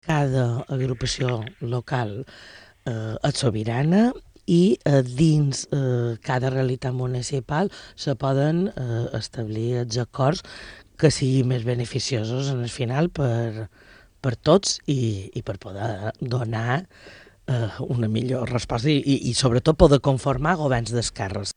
En declaracions al programa Al Dia, ha volgut deixar clar que cada assamblea municipal podrà pendre les decissions que consideri oportunes per formar governs d’esquerres.
busquets.mp3